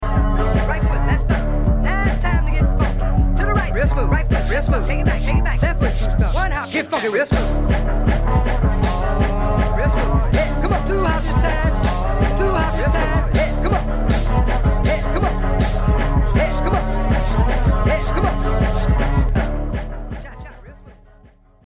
jersey club remix